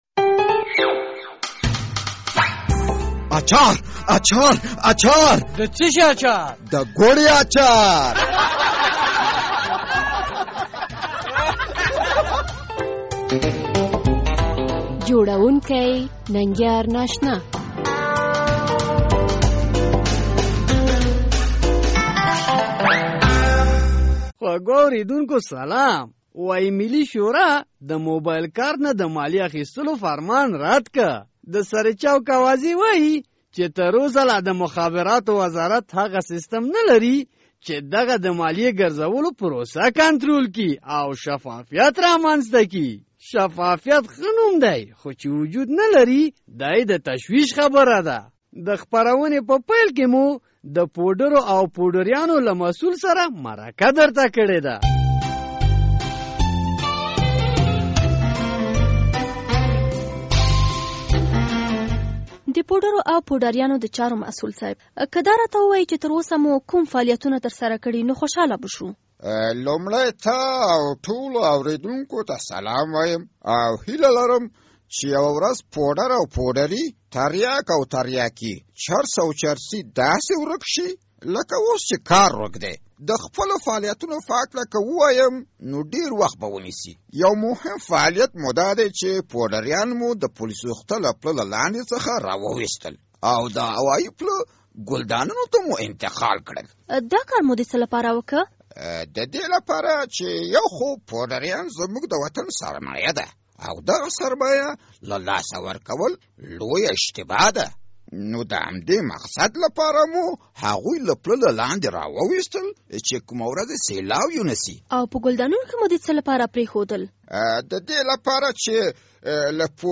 د ګوړې اچار په دې خپرونه کې لومړی د پوډرو او پوډریانود چارو له مسول سره مرکه شوې ده او دا ورڅخه پوښتل شو...